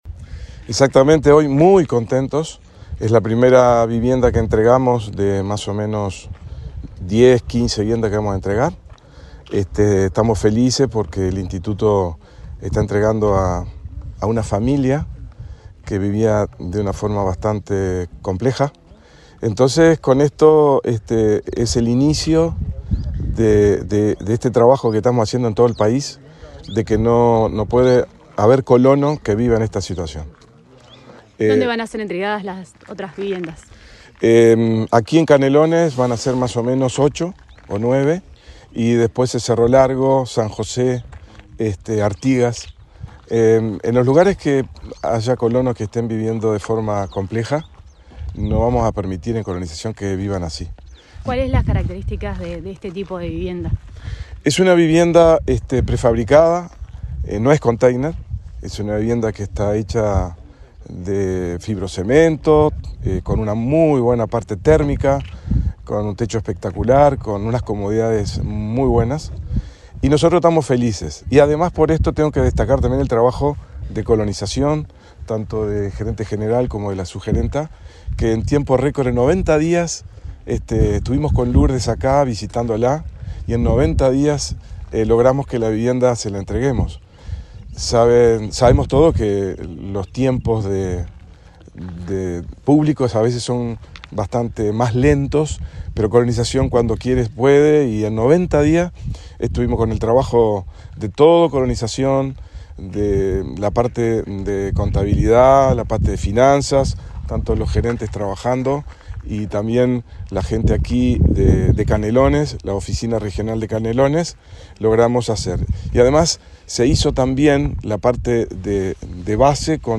Entrevista al vicepresidente del INC, Walter Rodríguez
Entrevista al vicepresidente del INC, Walter Rodríguez 29/08/2023 Compartir Facebook X Copiar enlace WhatsApp LinkedIn El vicepresidente del Instituto Nacional de Colonización (INC), Walter Rodríguez, dialogó con Comunicación Presidencial en Canelones, donde entregó una vivienda a colonos de la localidad de Tapia.